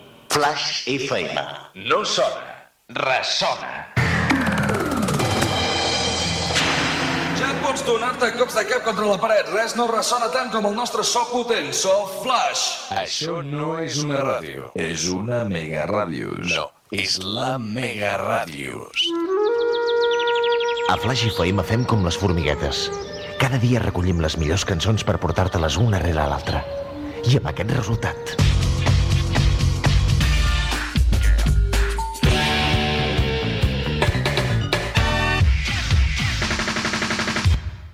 Indicatiu de l'emissora "ressona"